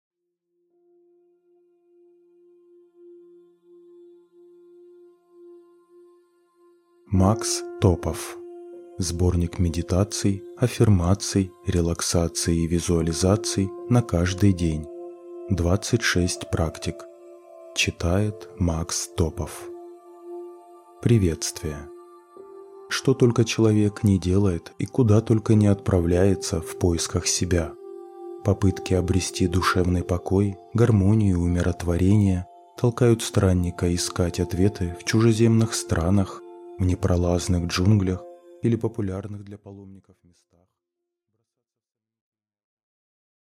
Аудиокнига Сборник медитаций, аффирмаций, релаксаций и визуализаций на каждый день. 26 практик | Библиотека аудиокниг